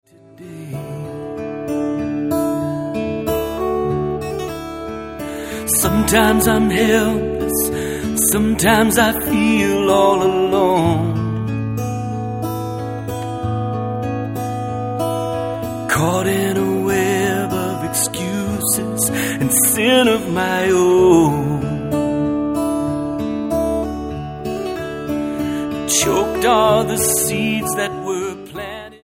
Sie enthält einen Mix von melodiösem Gitarrenrock, ...